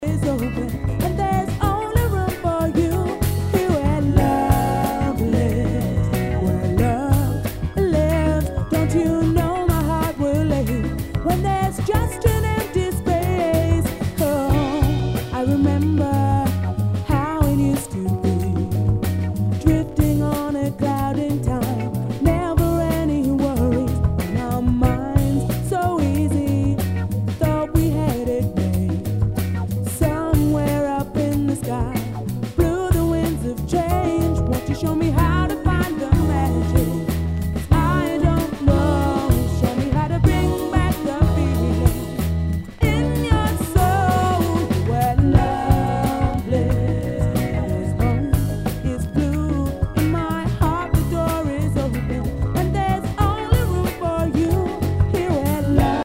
Nu- Jazz/BREAK BEATS
ナイス！アシッド・ジャズ　・ダウンテンポ！
全体にチリノイズが入ります。